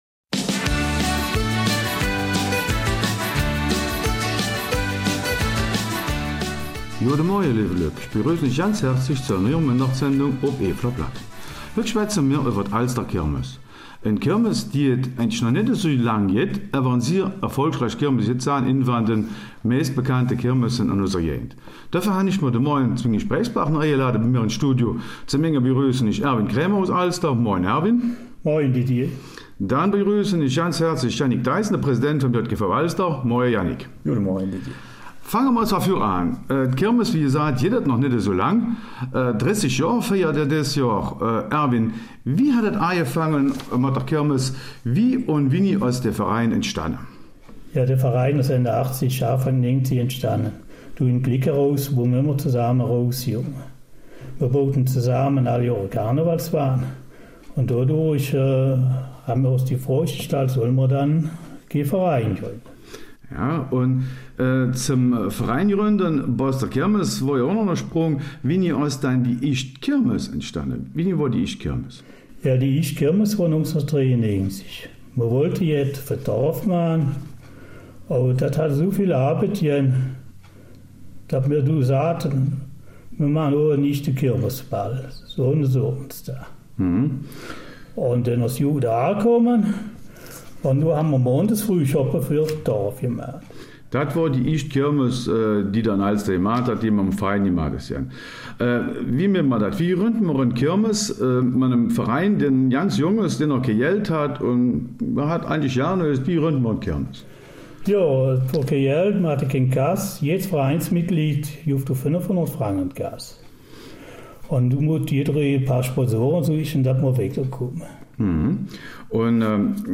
Eifeler Mundart: Kirmes in Alster